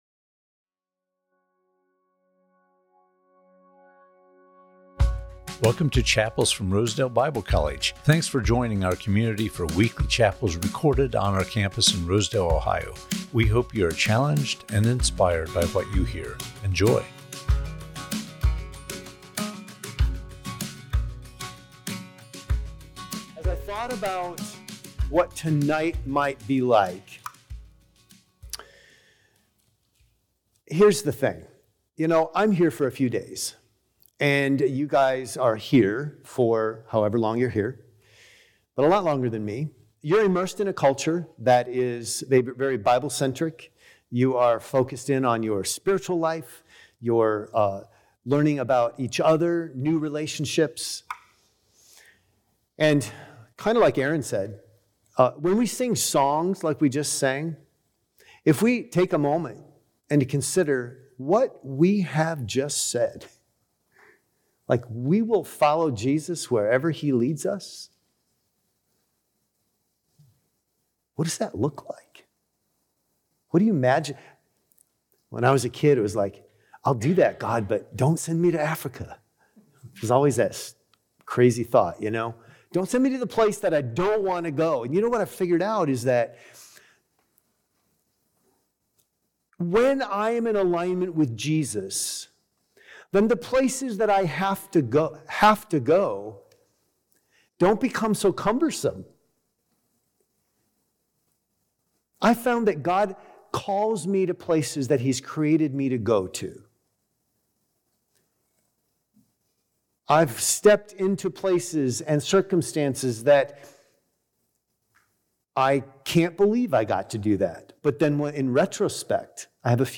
Chapels from Rosedale Bible College